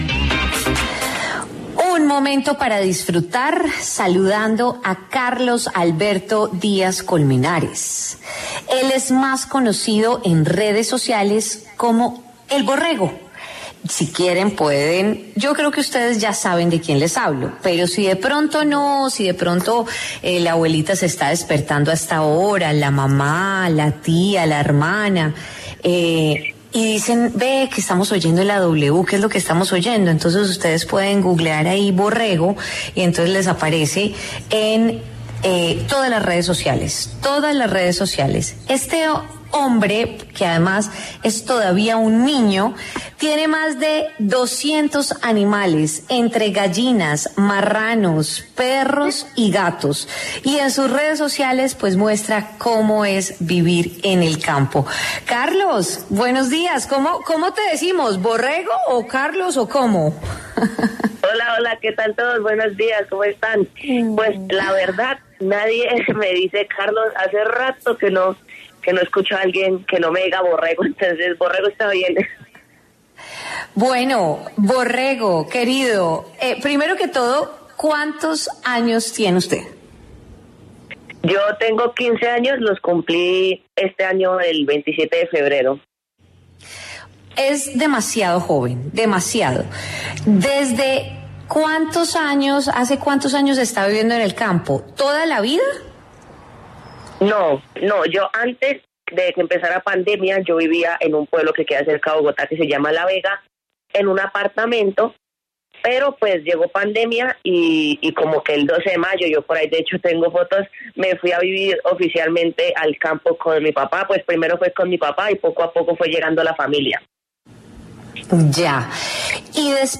habló en W Fin de Semana sobre su contenido.